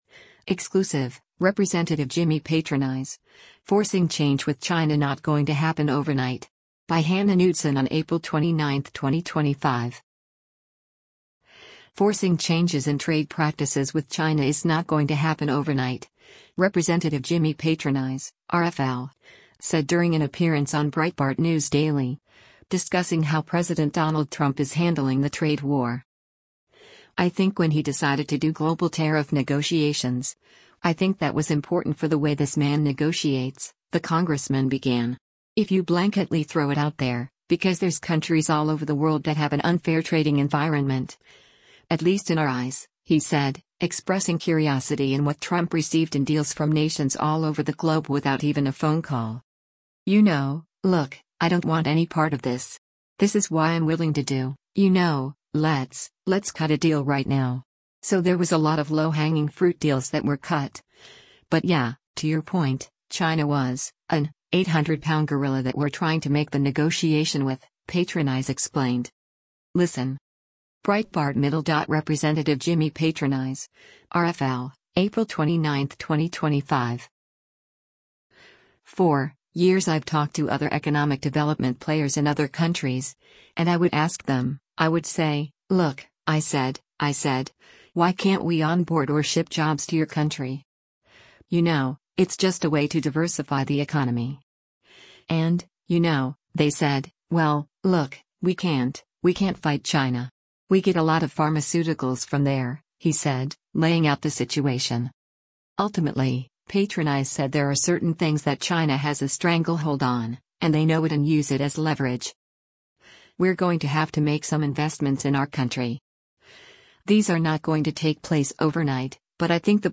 Forcing changes in trade practices with China is “not going to happen overnight,” Rep. Jimmy Patronis (R-FL) said during an appearance on Breitbart News Daily, discussing how President Donald Trump is handling the trade war.